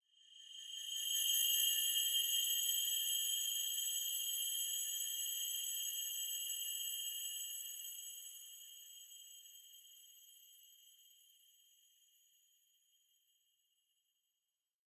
Ambiance atmosphere shimmer magic fantasy